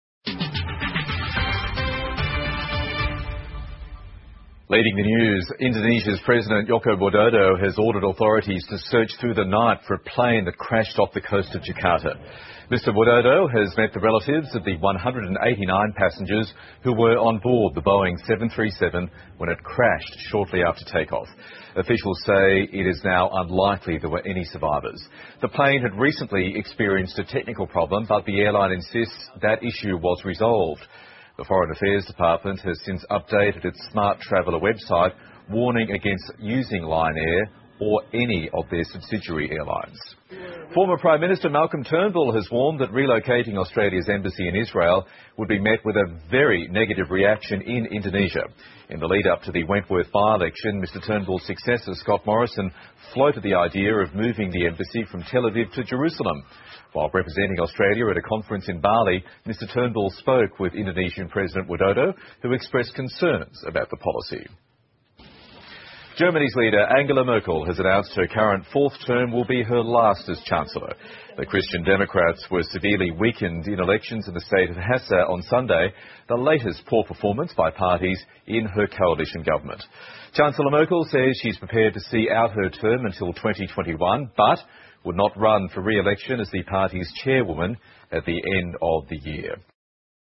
澳洲新闻 (ABC新闻快递) 印尼载189人客机坠毁 德国总理默克尔宣布将于2021年卸任 听力文件下载—在线英语听力室